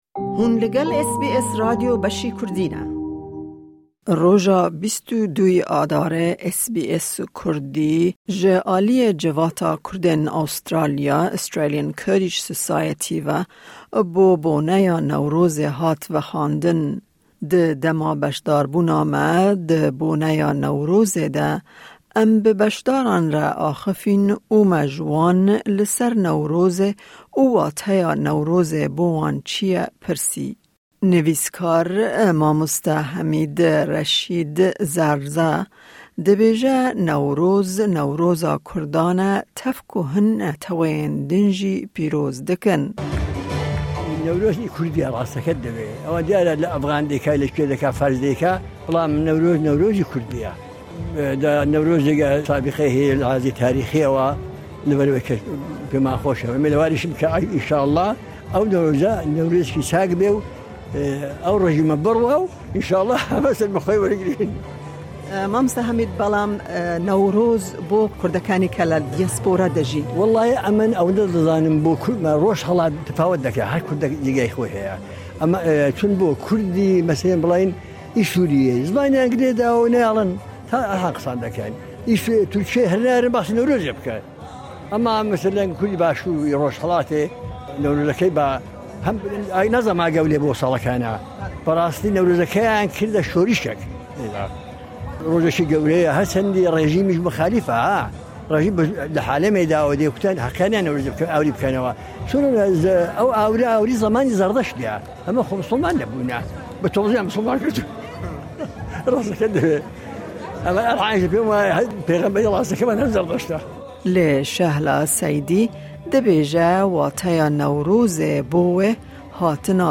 While attending the event, we engaged with participants and asked about the significance of Newroz in their lives.
Newroz event organised by Australian Kurdish Society Credit: SBS Kurdish